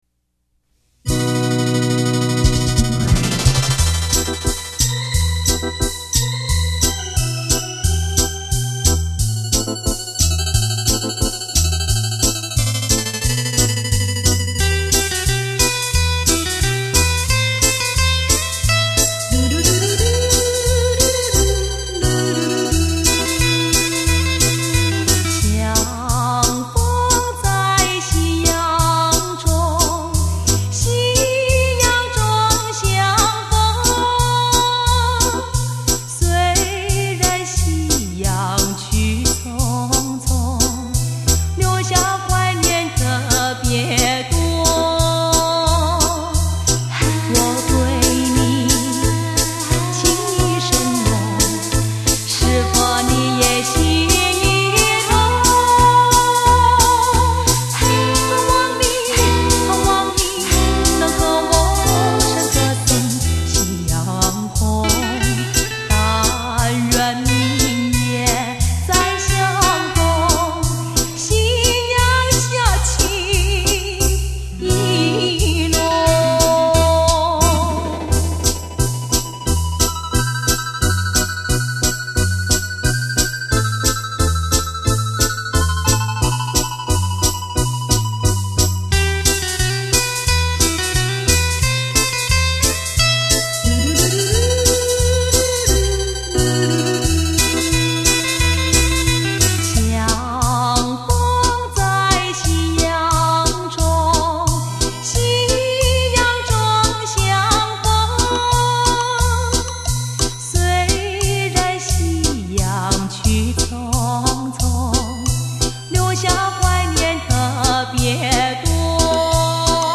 歌唱版    熟悉的旋律 令您回味无穷  立体演唱会 环绕身历声